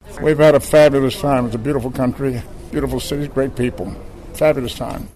Nimoy, best known for his Star Trek character, Mr. Spock, visited Alaska in June of 2013 on the cruise ship Silver Shadow. While he was in Ketchikan, KRBD caught up with him in time to ask for his thoughts on the 49th state.